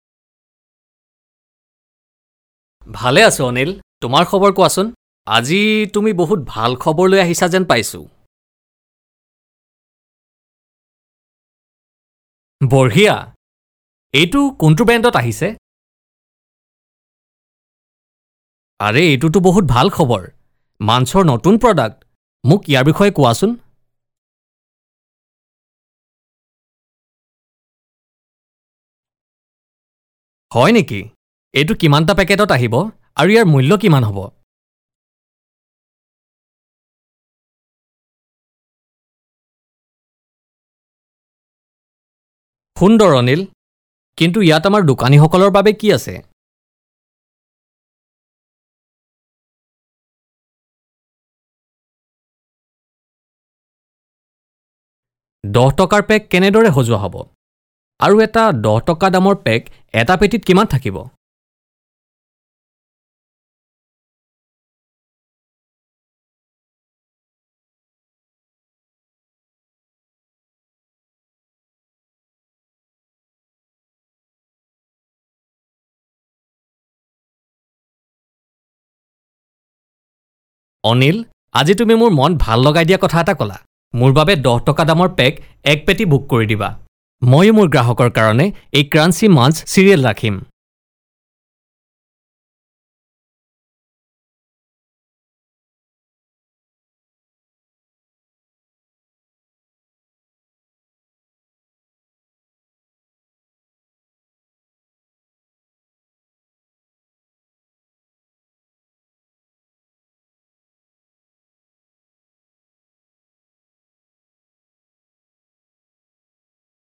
Professional Assamese Voice Artiste and Translator ....